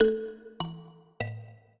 mbira
minuet7-2.wav